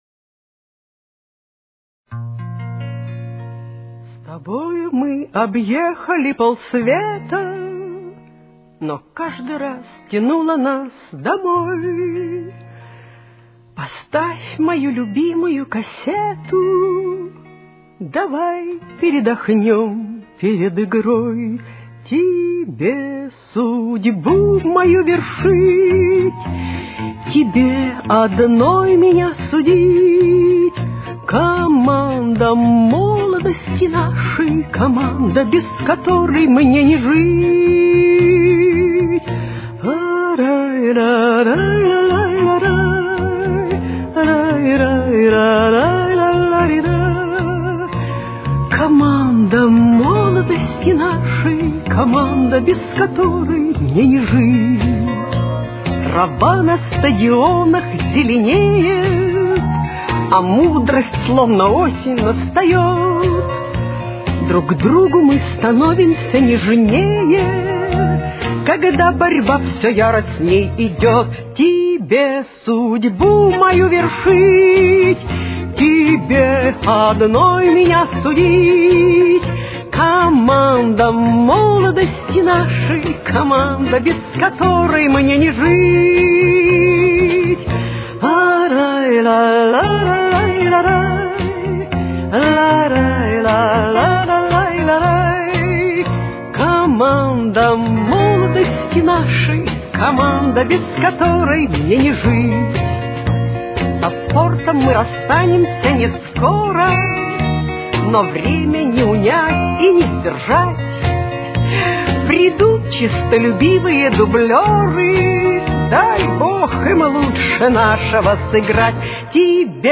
Си-бемоль минор. Темп: 115.